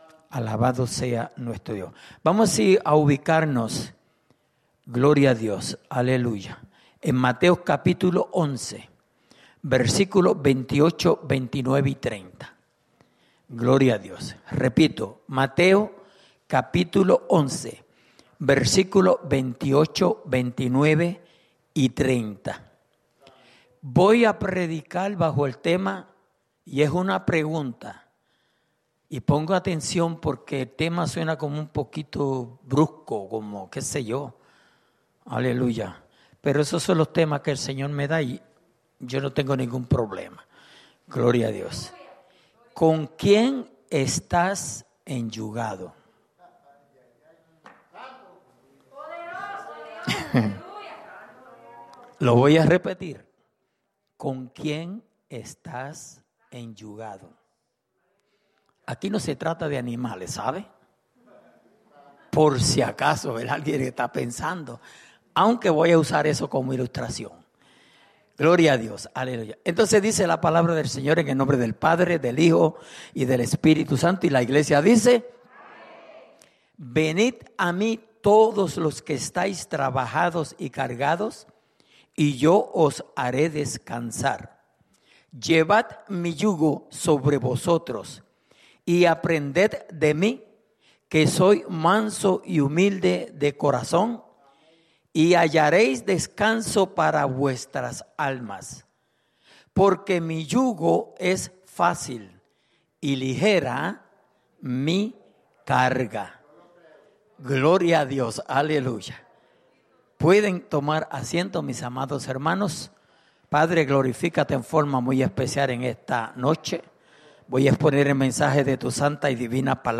Mensaje
grabado el 09/13/2020 en la Iglesia Misión Evangélica en Souderton, PA